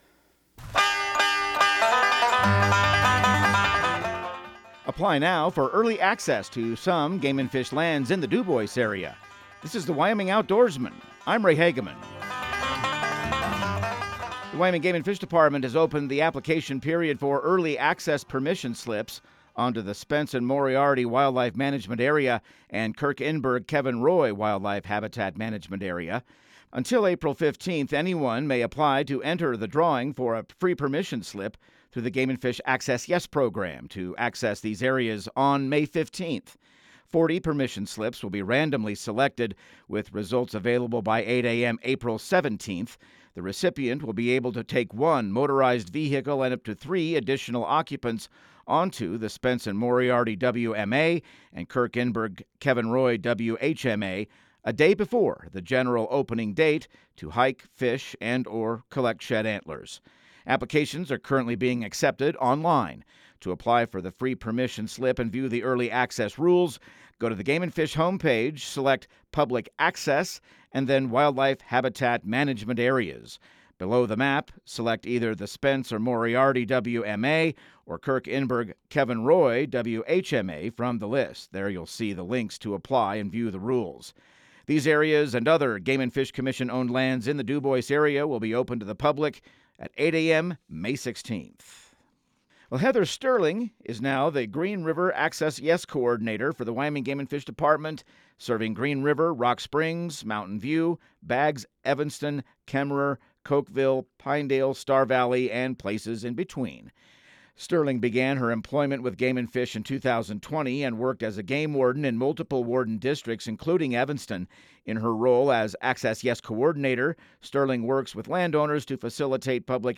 Radio news | Week of March 2